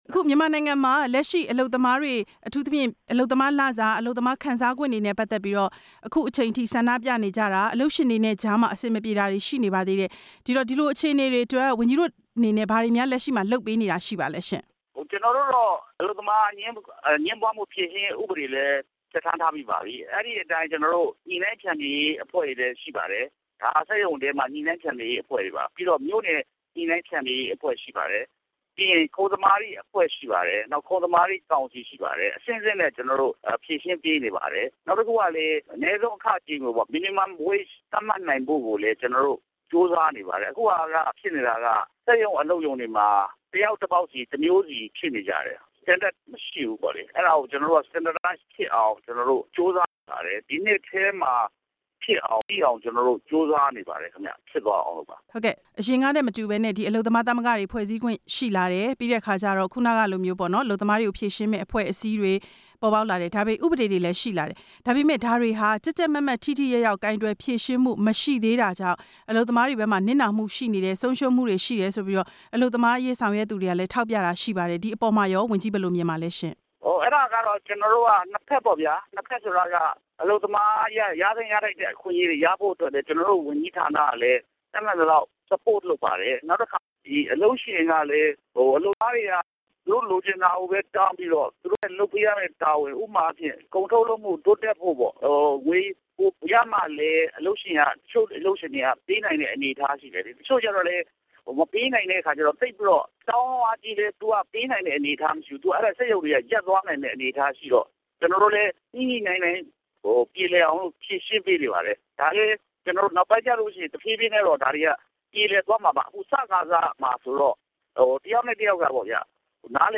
အလုပ်သမား ဒုဝန်ကြီး ဦးထင်အောင်နဲ့ မေးမြန်းချက်